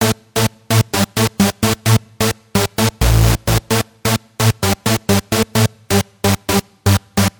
标签： 130 bpm House Loops Synth Loops 1.24 MB wav Key : Unknown
声道立体声